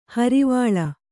♪ harivāḷa